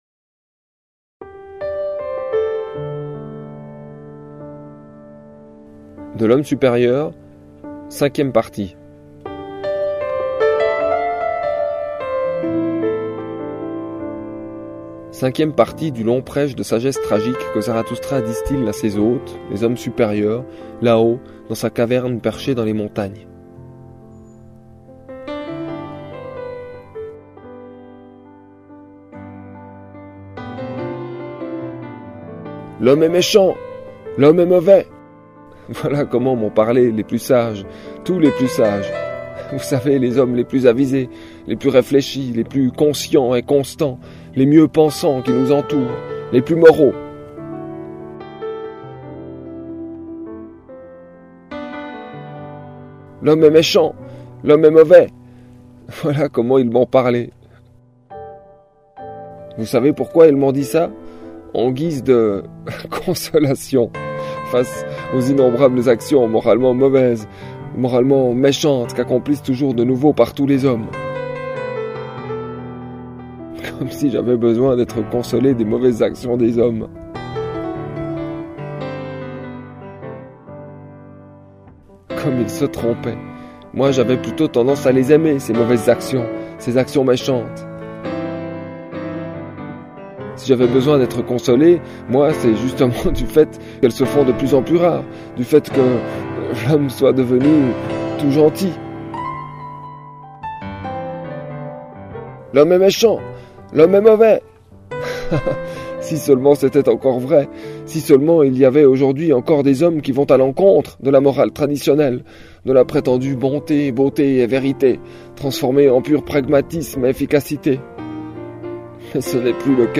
Musique : Keith Jarrett, Köln Concert, 1975.